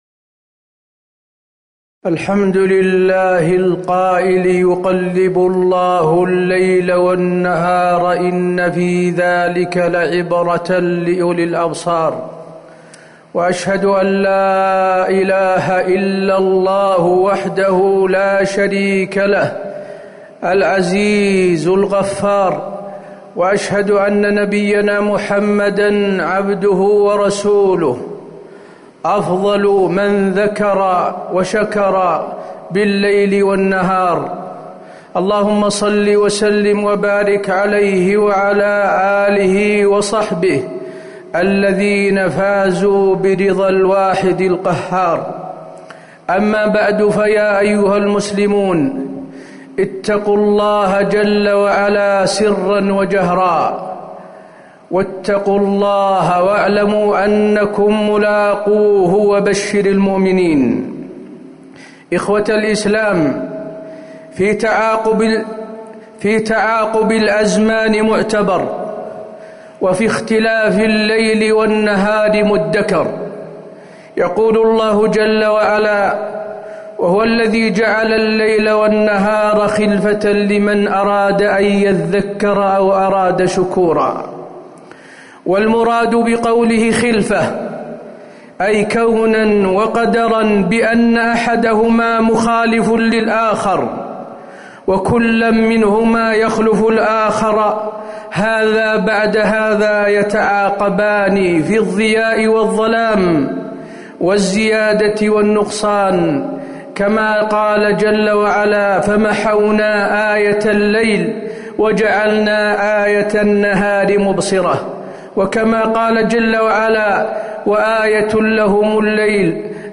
تاريخ النشر ٢ ذو القعدة ١٤٤٠ هـ المكان: المسجد النبوي الشيخ: فضيلة الشيخ د. حسين بن عبدالعزيز آل الشيخ فضيلة الشيخ د. حسين بن عبدالعزيز آل الشيخ الإعتبار باختلاف الأزمان